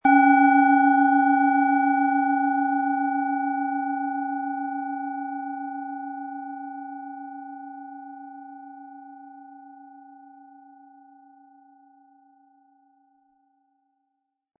Tibetische Universal-Klangschale, Ø 10,9 cm, 100-180 Gramm, mit Klöppel
Ein schöner Klöppel liegt gratis bei, er lässt die Klangschale harmonisch und angenehm ertönen.
SchalenformOrissa
MaterialBronze